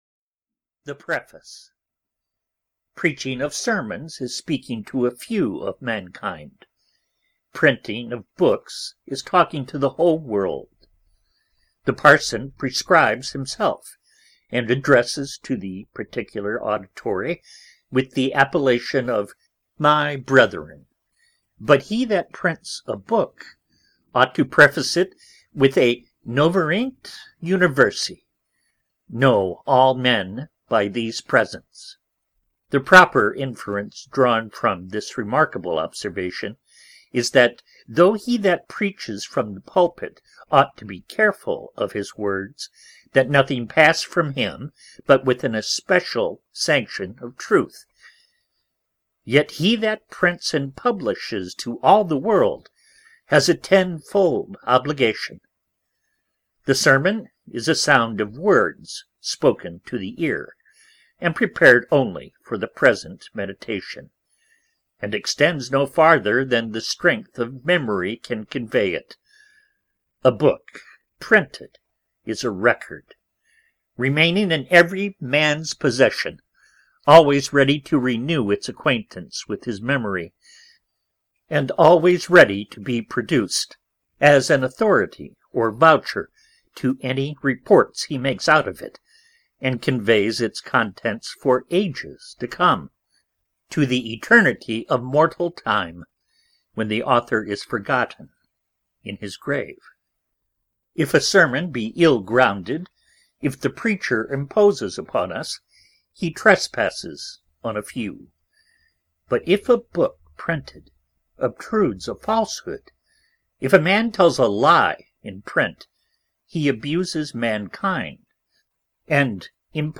The Storm (EN) audiokniha
Ukázka z knihy